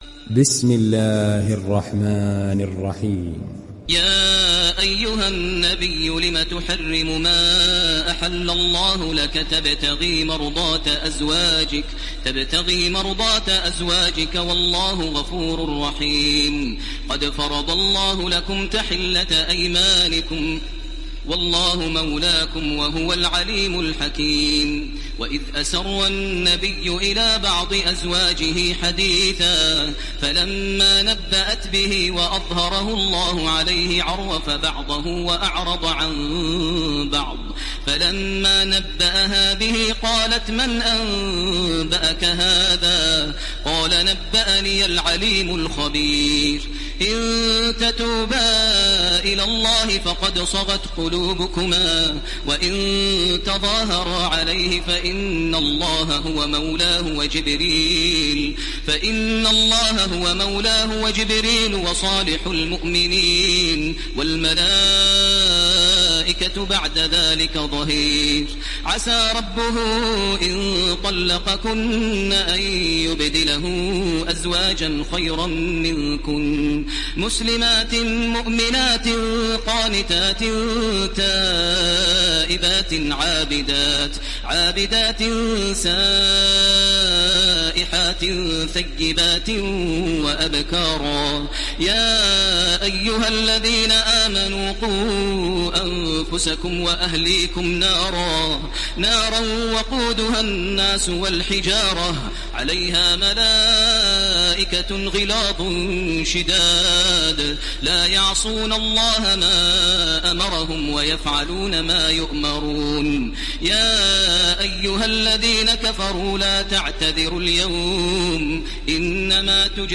Tahrim Suresi İndir mp3 Taraweeh Makkah 1430 Riwayat Hafs an Asim, Kurani indirin ve mp3 tam doğrudan bağlantılar dinle
İndir Tahrim Suresi Taraweeh Makkah 1430